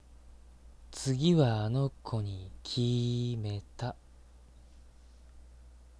セリフ
1番目の台詞は、好きな子に彼氏がいても、お色気でアタックする感じです。 2番目の台詞は、次のターゲットを見つけた時に、イジワルっぽく色気を含ませて呟く感じです。